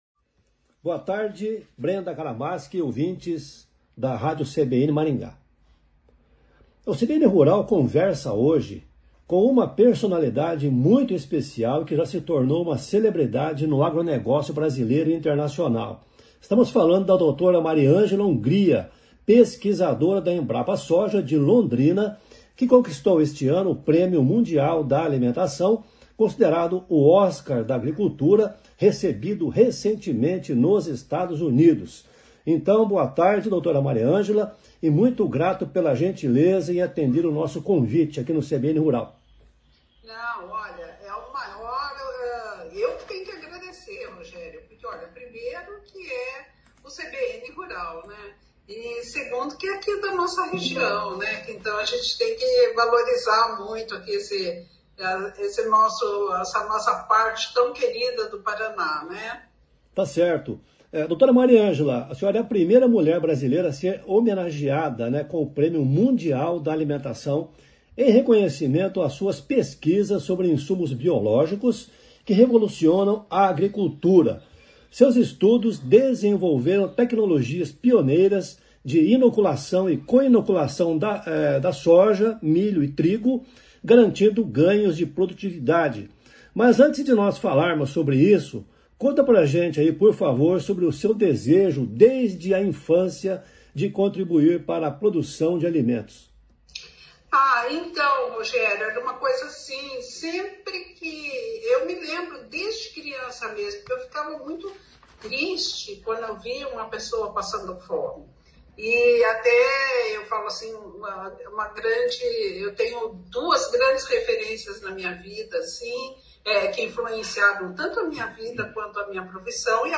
O CBN Rural entrevistou a pesquisadora Mariângela Hungria, primeira mulher brasileira a ganhar Prêmio Mundial da Alimentação.